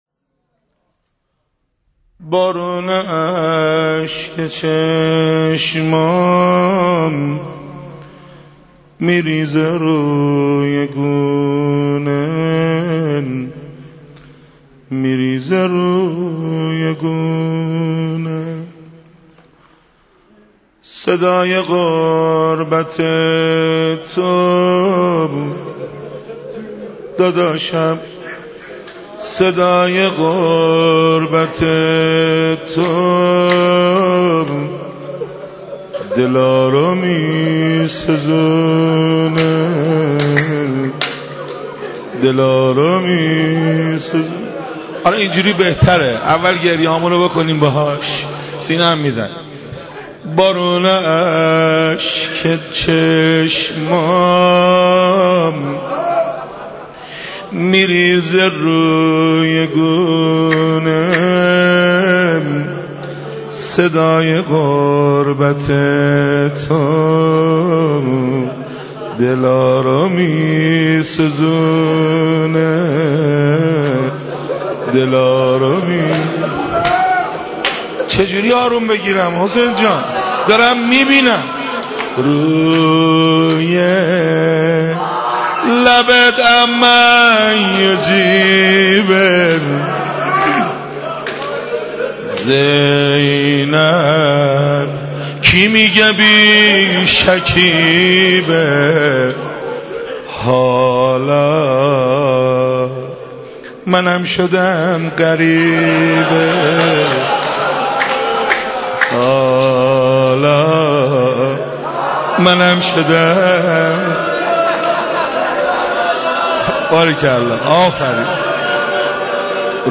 حسینیه امام حسین علیه السلام'شب پنجم :: از علم حقوق بیشتر بدانید.